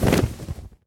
wings2.ogg